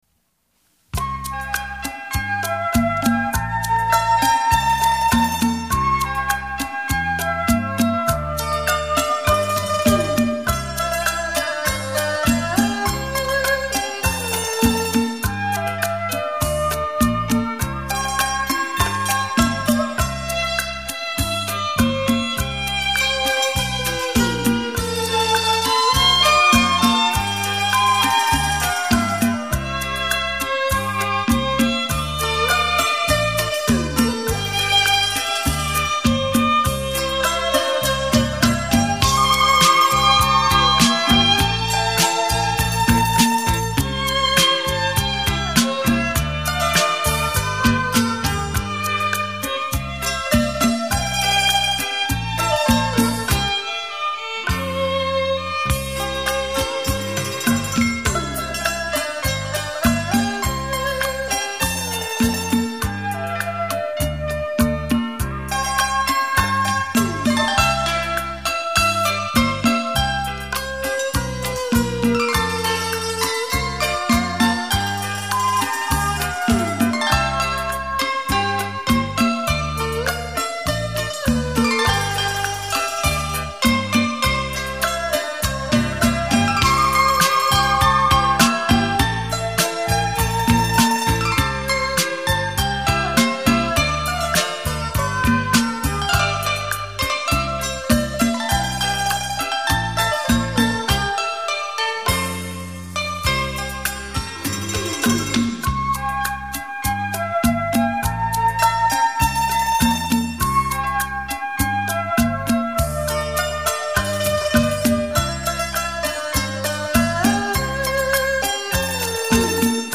音乐类型: 民乐
优美的旋律，熟悉的华乐，配上西乐大合奏，给您浑然不同的清新感觉。